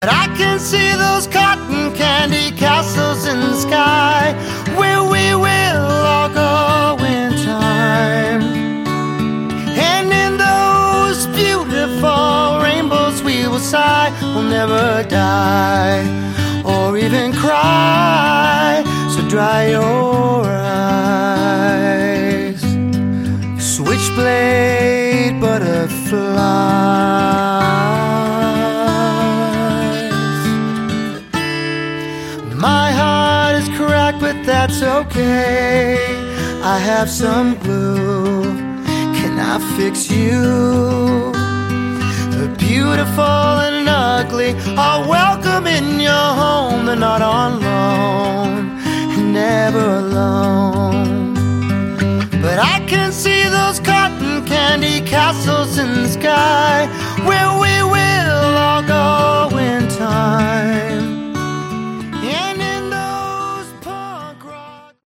Category: Hard Rock
vocals, guitar
bass
drums